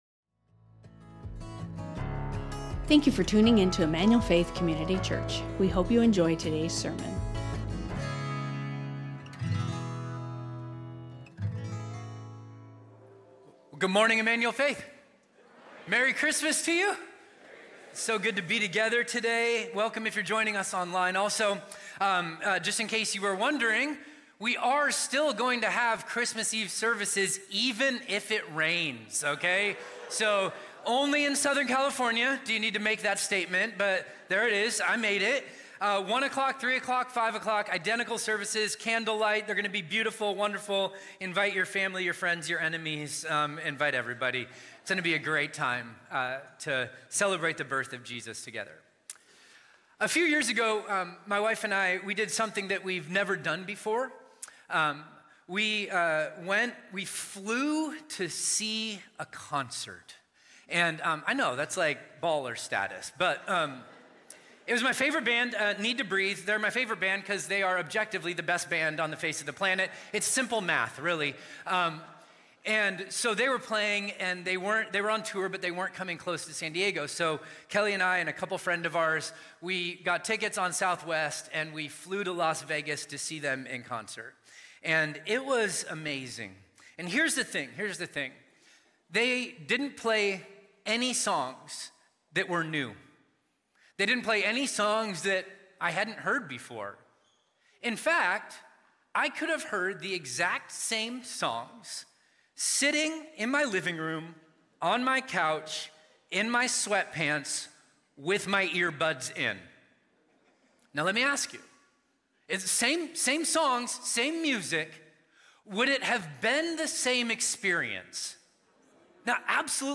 Emmanuel Faith Sermon Podcast The Final Five | Matthew 2:1-12 Dec 22 2025 | 00:43:38 1x 00:00 / 00:43:38 Subscribe Share Spotify Amazon Music RSS Feed Share Link Embed